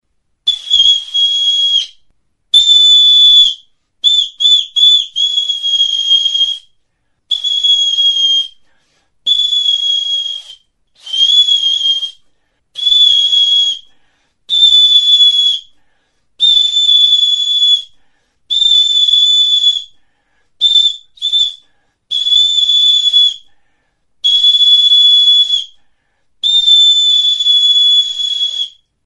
Aerófonos -> Flautas -> Recta (de una mano) + flautillas
Grabado con este instrumento.
TXULUBITA; TXIFLOA (LATORRIA)
Latorrizko txaparekin egindako txifloa edo zulorik gabeko flauta sinplea da.
METAL; HOJALATA